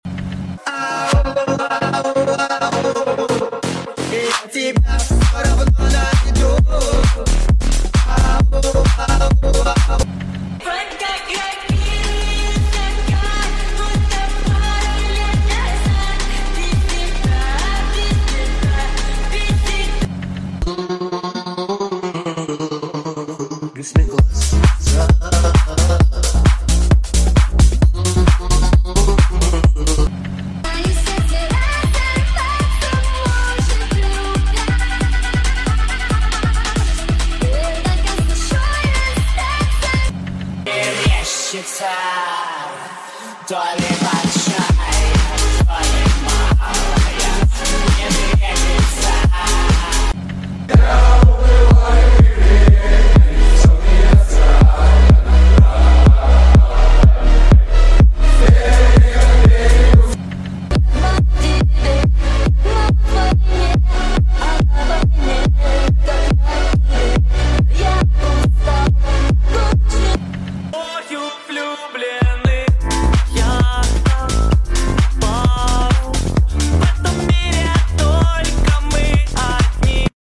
In Car 2 (x8) Pump Sound Effects Free Download